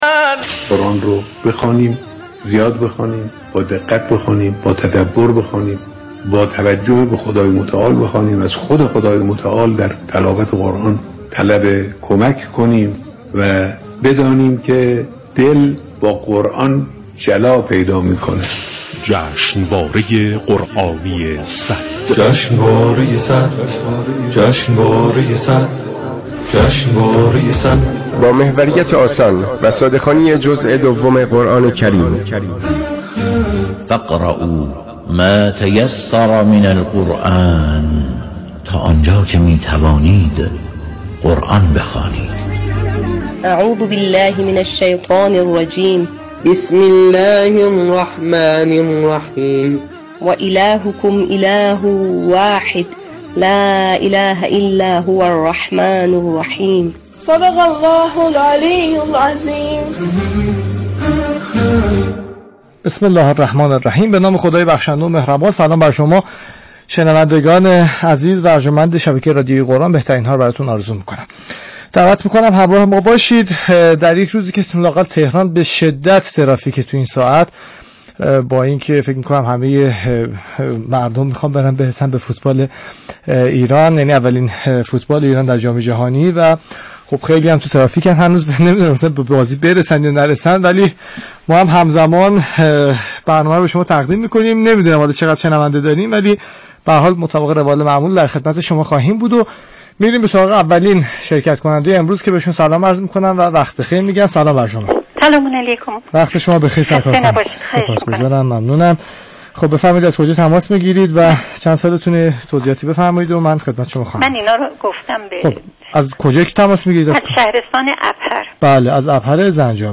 طی برنامه با حدود 10 مخاطب از شهرهای مختلف ارتباط گرفته می‌‌شود و انتقادات و پیشنهادات آنها شنیده می‌شود و در حین اجراها نیز شاهکارهایی از اناشید، ابتهال‌ها و سروده‌های ناب به سمع و نظر مخاطبان می‌رسد.